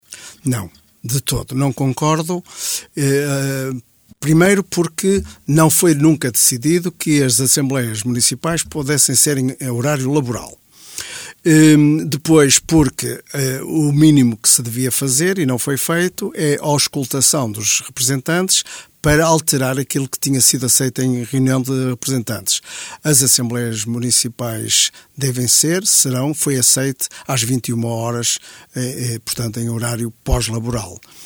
Abílio Cerqueira (BE) nos estúdios da Rádio Caminha